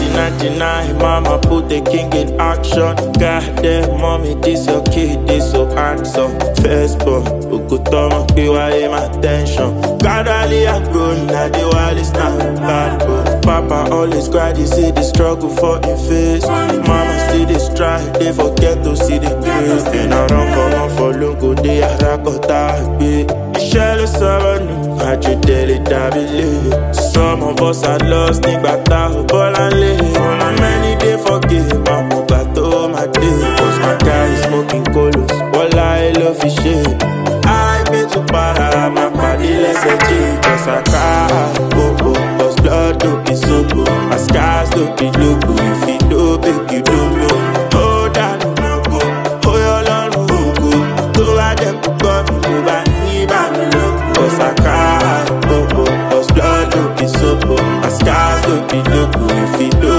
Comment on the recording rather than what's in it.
the Location’s Ambiance